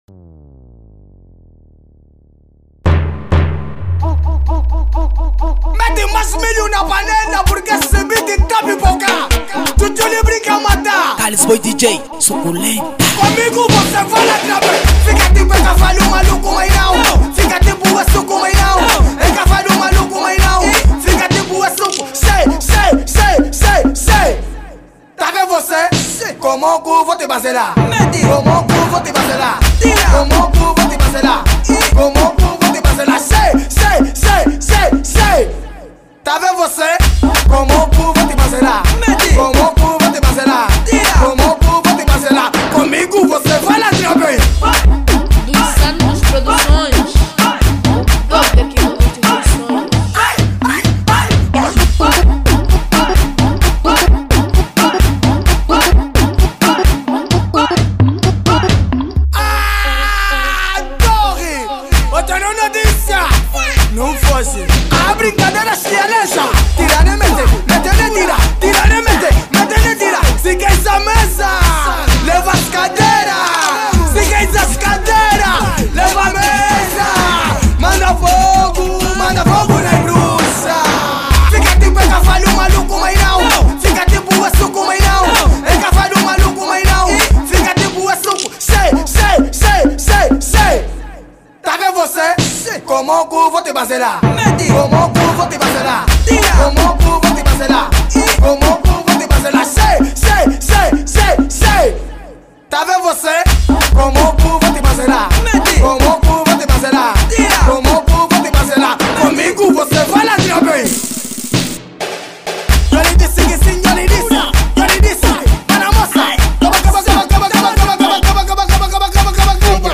Categoria Afro House